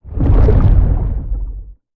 Sfx_amb_treespire_ventgarden_mouthopen_01.ogg